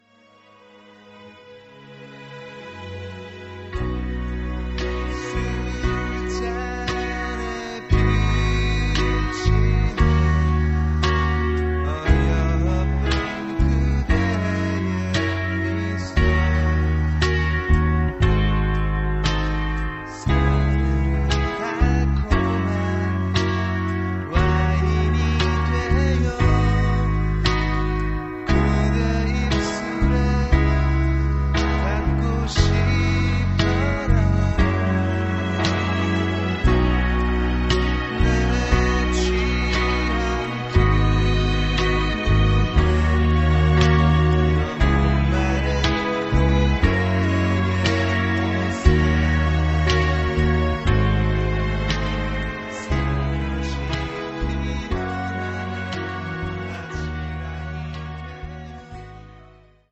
음정 -1키 5:57
장르 가요 구분 Voice Cut